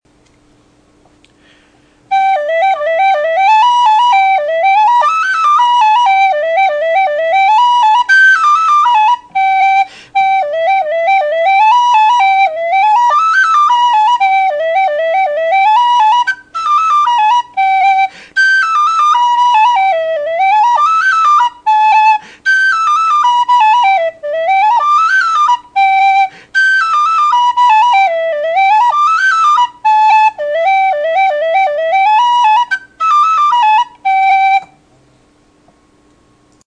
Whistle Reviewed: Syn D in aluminum
Well made, strong toned, pure, and sweet.
Pure and sweet, with good volume and fast action, with a little complexity and chiff in the 2nd octave.
Sound clips of the whistle:
Volume: A louder than average, and carries well.